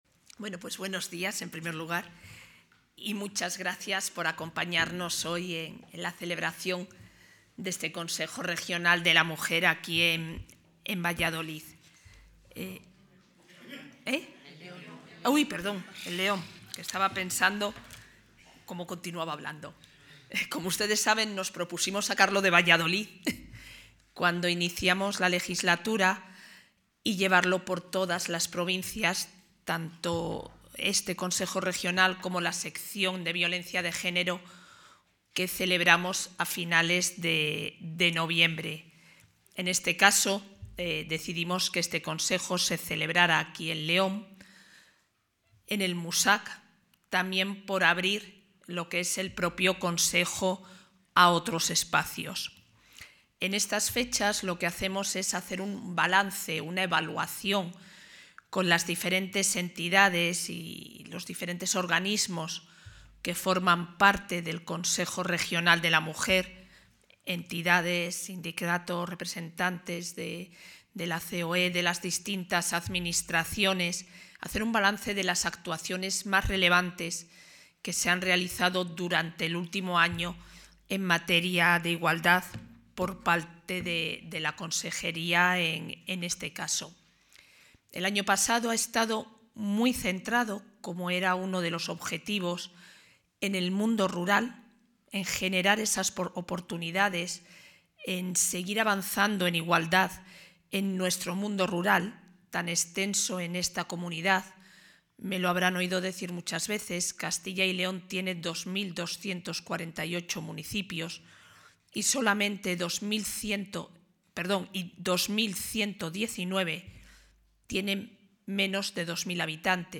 Declaraciones de la vicepresidenta.